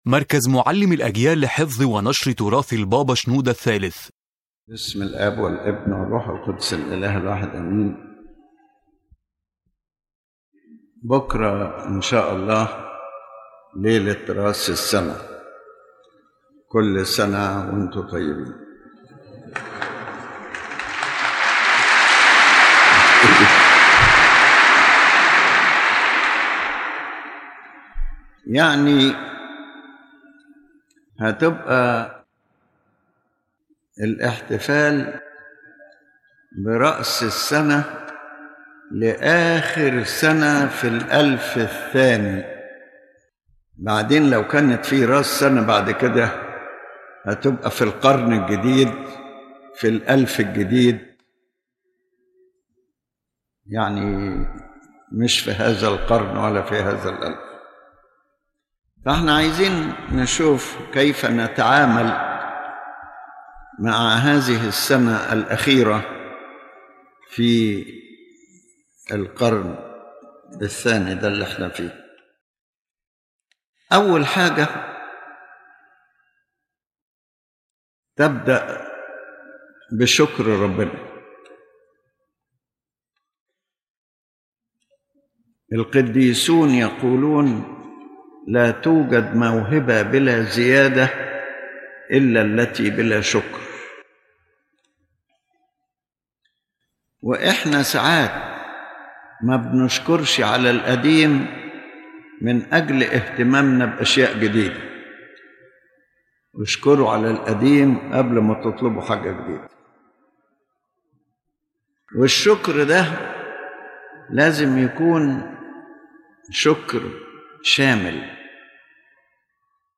His Holiness Pope Shenouda III speaks about how to receive the New Year with a conscious Christian spirit—not as a mere temporal celebration, but as a deep spiritual opportunity for renewal, beginning with thanksgiving, extending to self-examination, and ending with spiritual disciplines that lead to true growth.